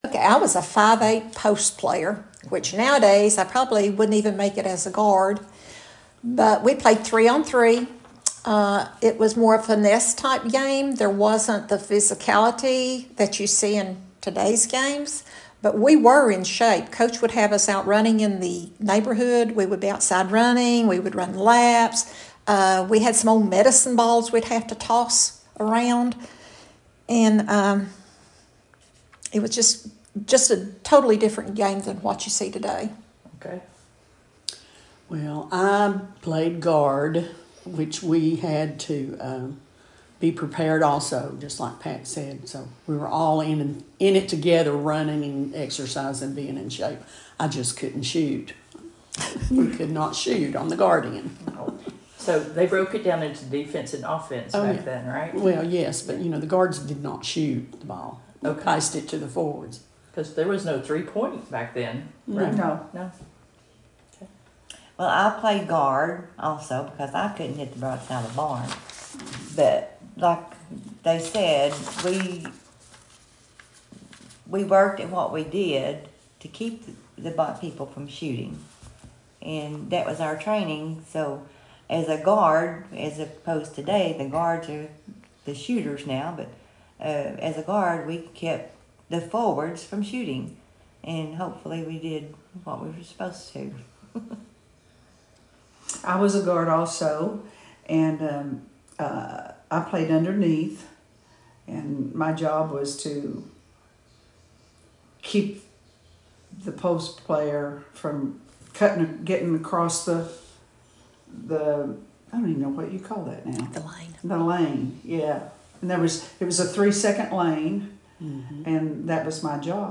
An Audio Interview